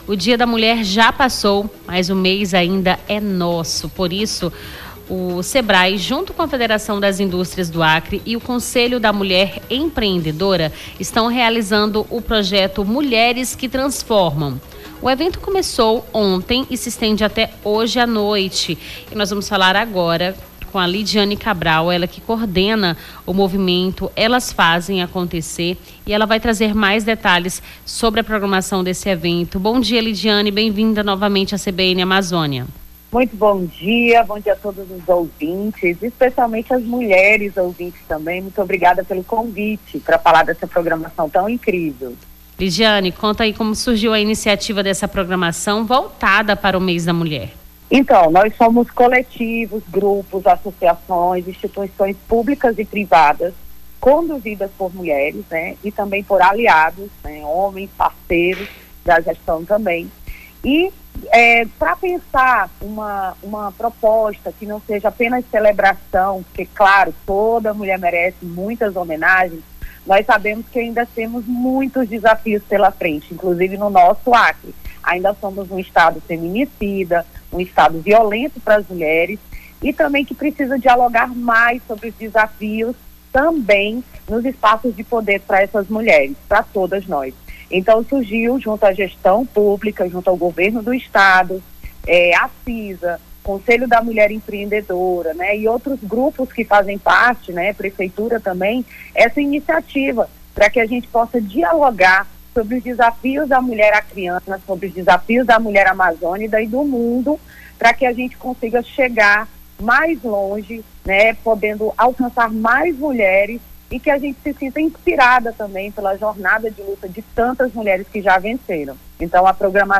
Nome do Artista - CENSURA - ENTREVISTA MULHERES QUE TRANSFORMAM (19-03-25).mp3